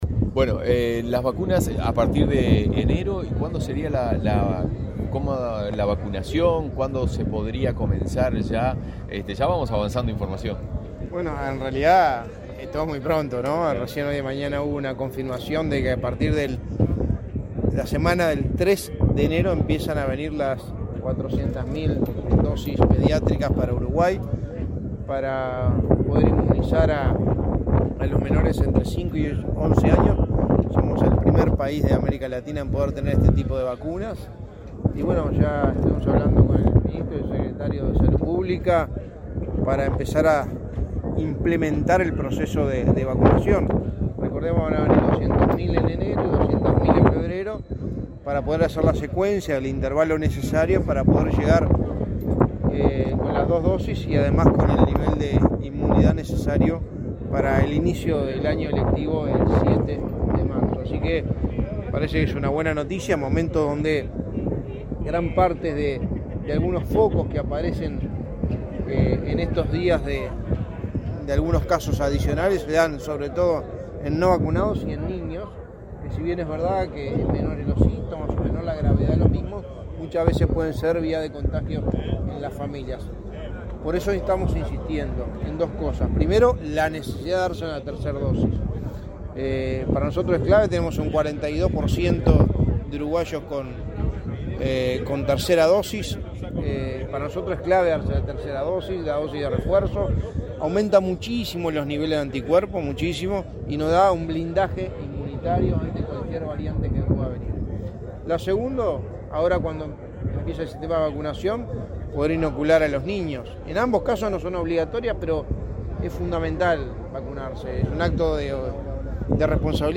Declaraciones a la prensa del secretario de Presidencia, Álvaro Delgado
El secretario de Presidencia, Álvaro Delgado, dialogó con la prensa, luego de participar de la ceremonia por el Día de la Policía.